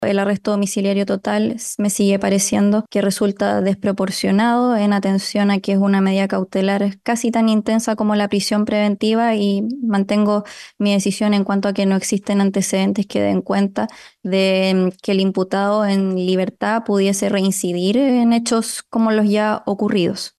Durante la audiencia, la magistrada Catalina Donoso señaló que el arresto domiciliario total era una medida desproporcionada para este caso.